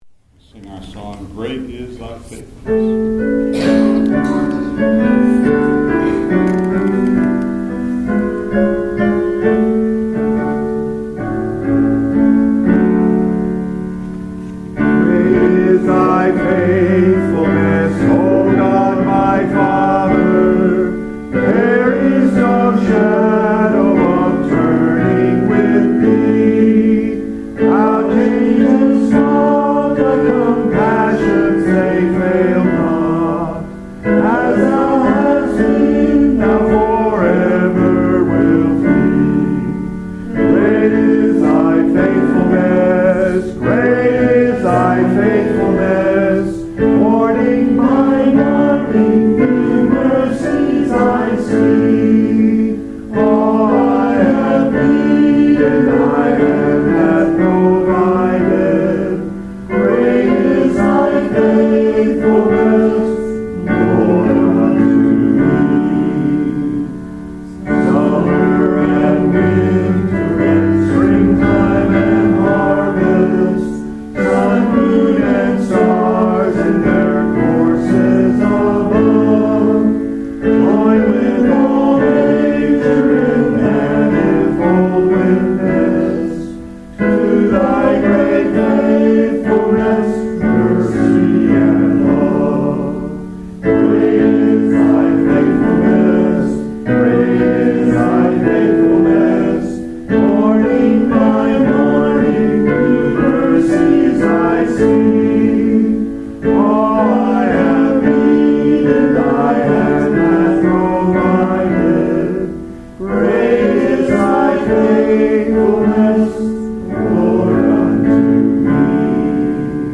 Message: “A Family of Friends” Scripture: 2 Timothy 4:9-11, 16, 21